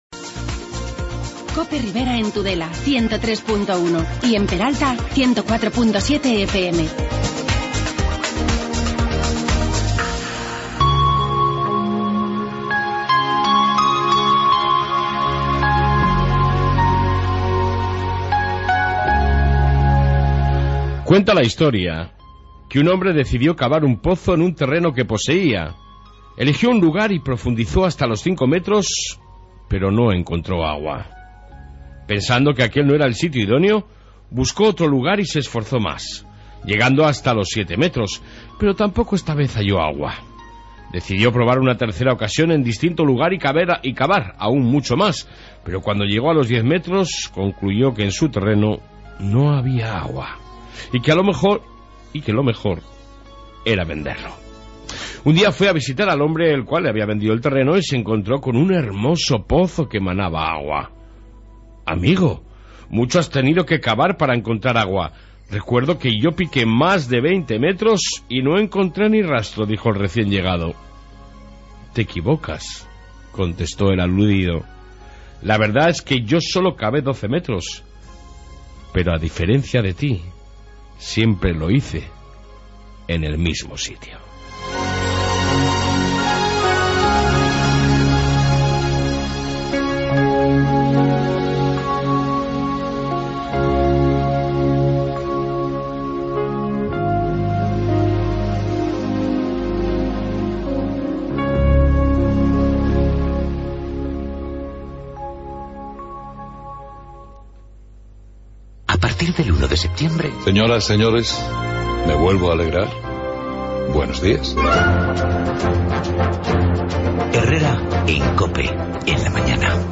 AUDIO: Informativo ribero con Policia Municipal, noticias sobre el Hospital, la Uned, etc...